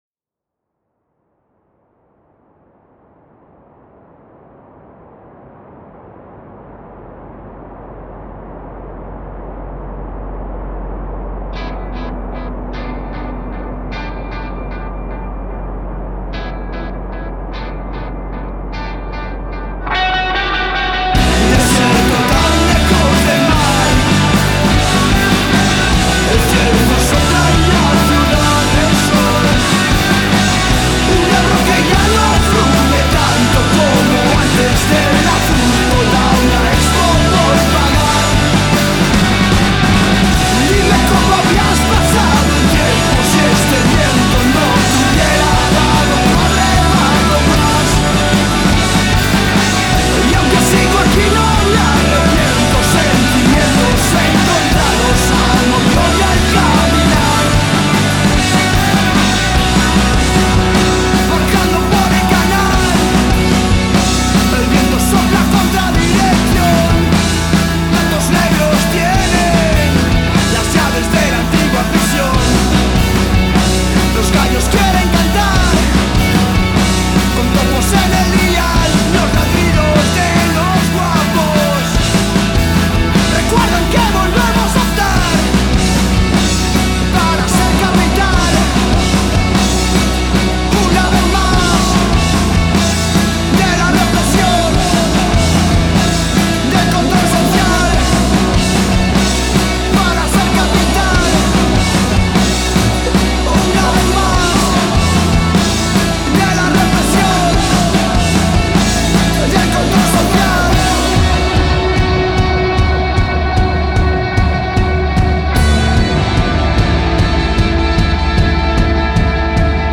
Groupe espagnol
punk, emo et post hardcore